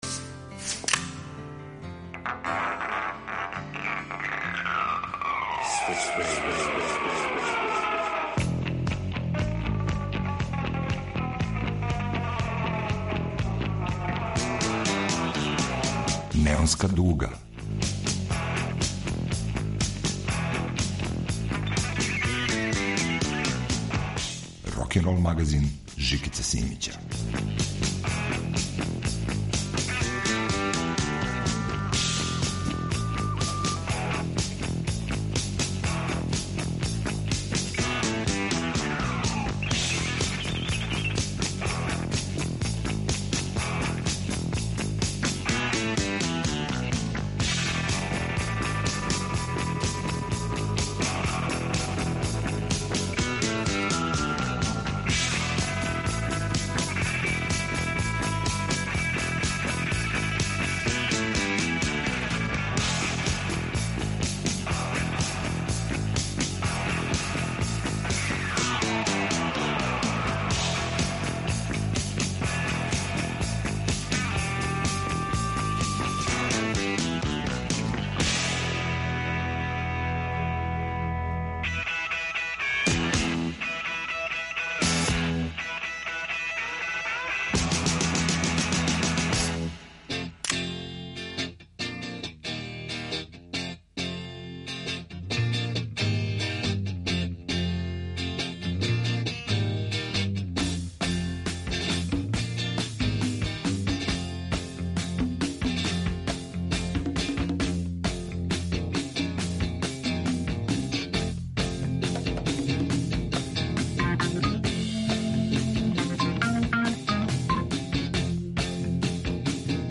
Песме су нове и старе, рокерски 100% правоверне.